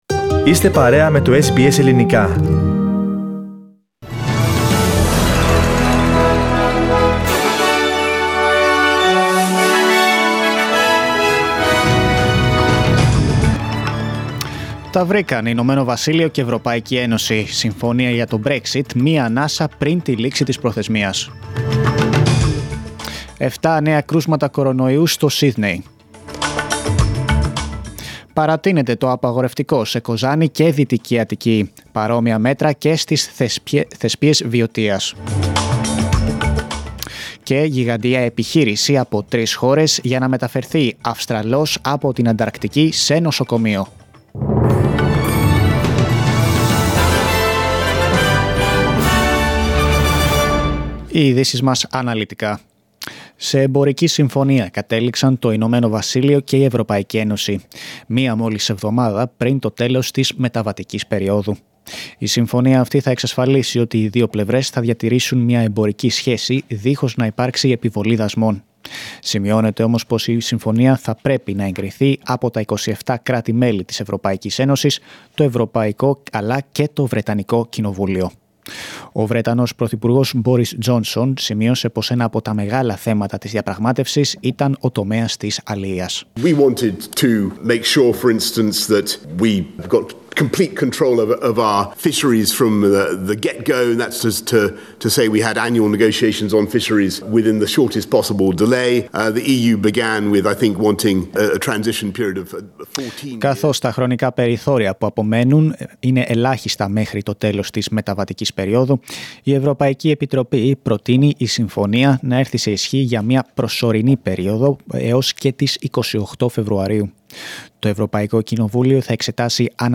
Δελτίο Ειδήσεων Παρασκευή 25.12.20